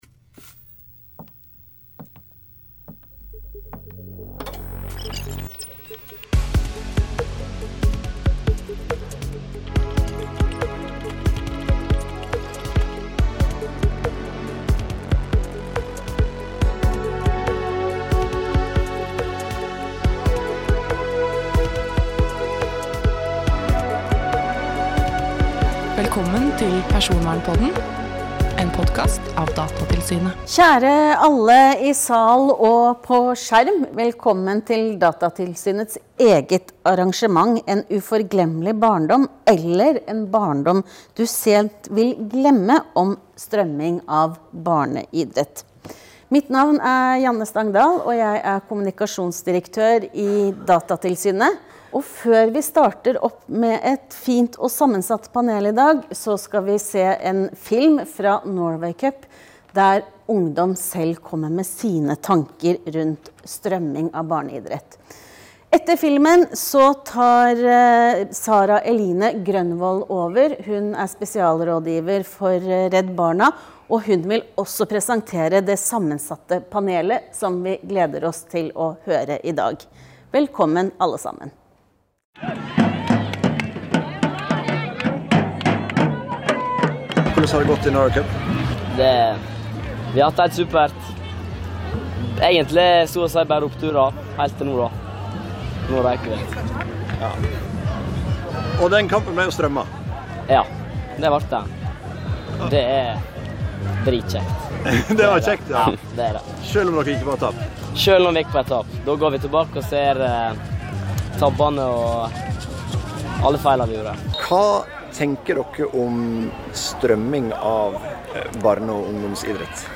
#6 Arendalsuka 2023: Debatt om strømming av barneidrett
Under Arendalsuka 2023 inviterte Datatilsynet til debatt om temaet strømming av barne- og ungdomsidrett.
Dette er et opptak fra arrangementet.
arendalsuka_debatt-om-barneidrett.mp3